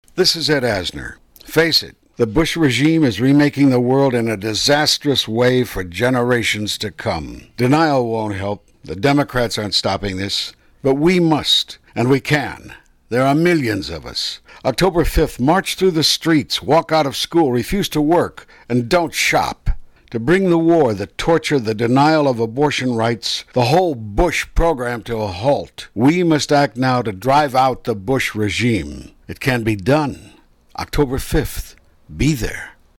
§Ed Asner PSA